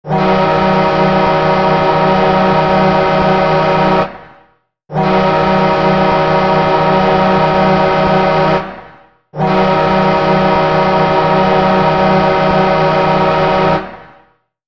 Play, download and share Red Wings Goal Horn original sound button!!!!
red-wings-goal-horn.mp3